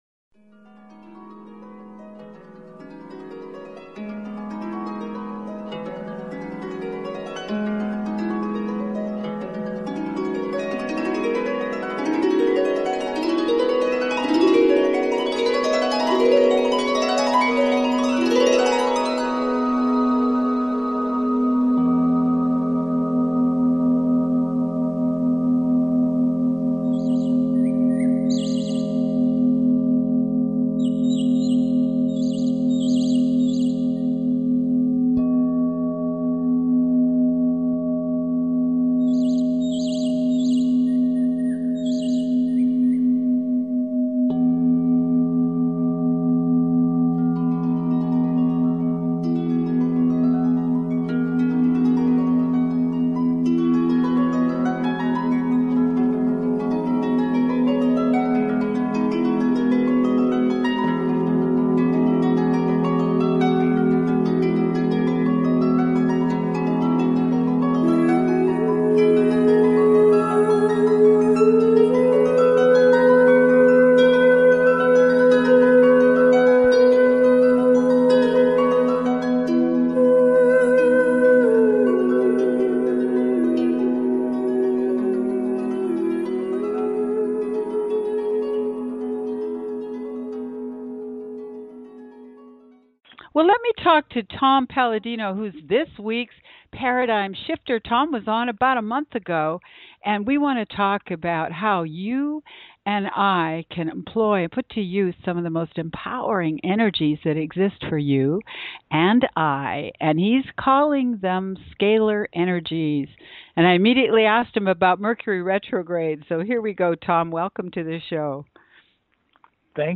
Paradigm Shifters interview